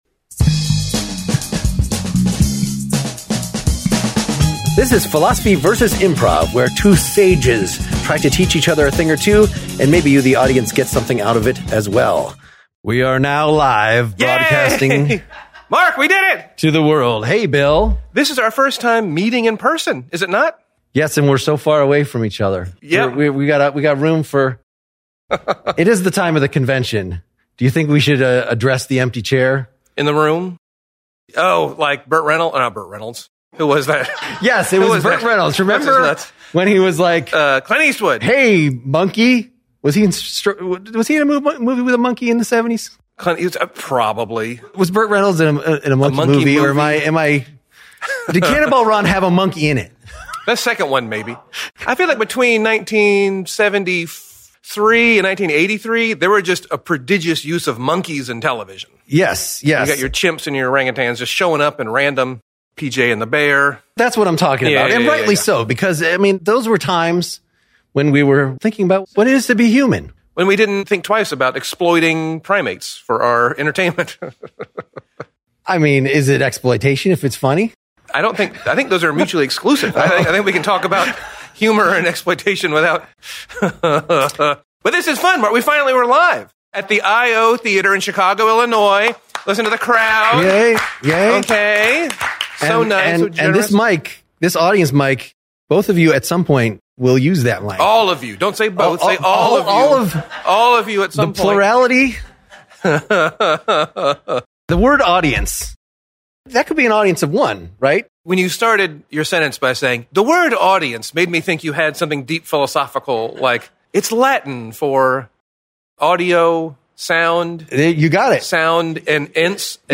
PEL Presents PvI#82: LIVE in Personability w
They acted out scenes (while still sitting!) about an unsuccessful party and Experiences-R-Us.
You may choose to watch the proceedings live on stage.